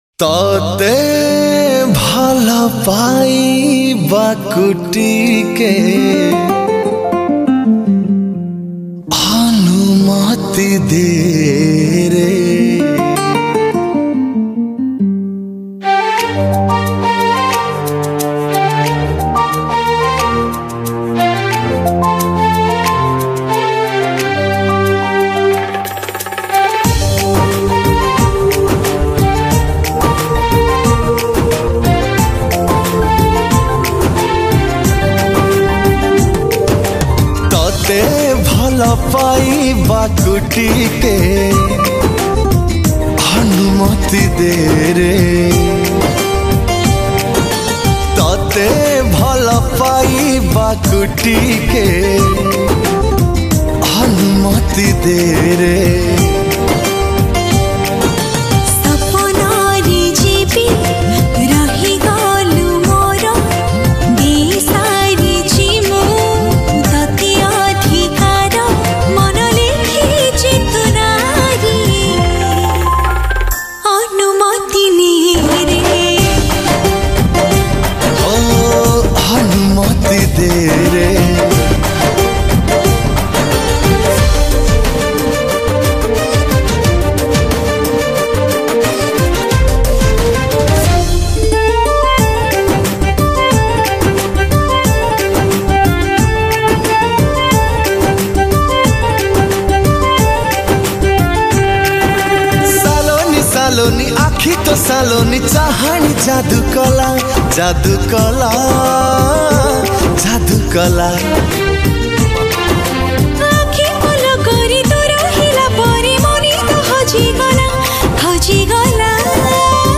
New Odia Romantic Song